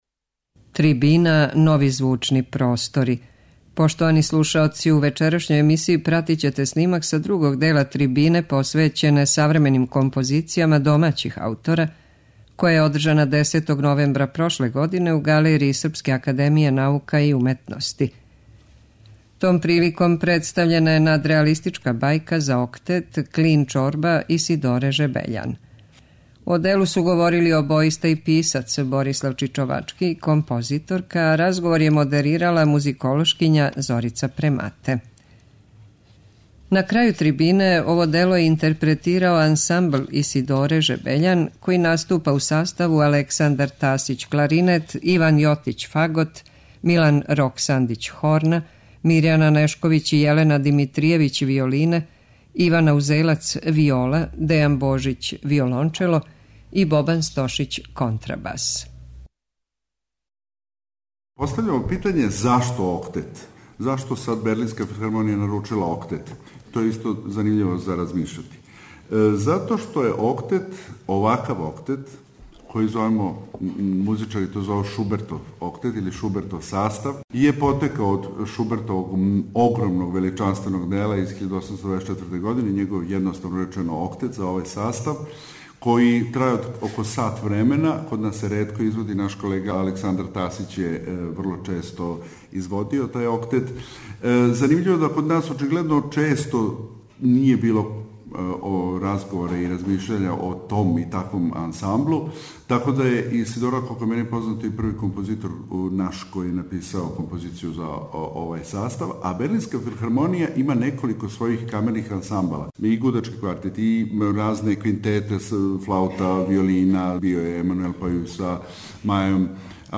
Исидора Жебељан: Клин чорба, надреалистичка бајка за октет
Слушаћете снимак другог дела трибине посвећене савременим композицијама домаћих аутора, која је одржана 10. новембра прошле године у галерији Српске академије наука и уметности.
кларинет
фагот
хорна
виолине
виола
виолончело
контрабас
Ова трибина одржана је у оквиру музичког фестивала БУНТ.